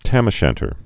(tămə-shăntər)